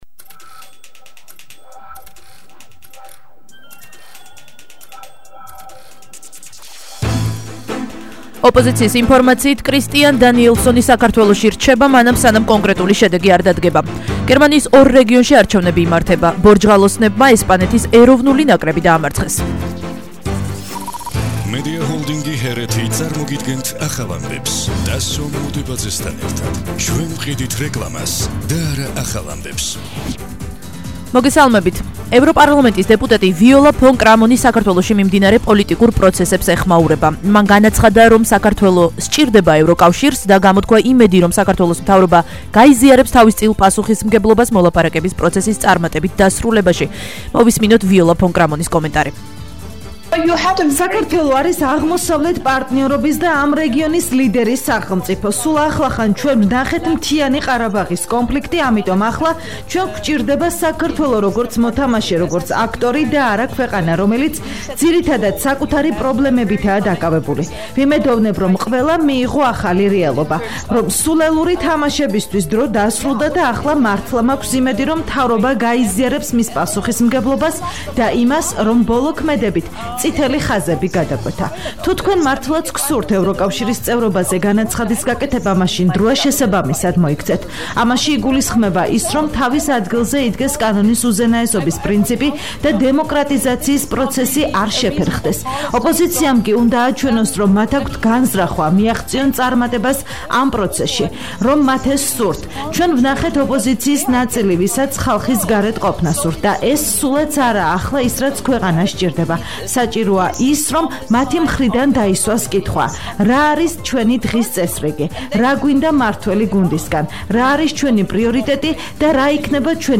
ახალი ამბები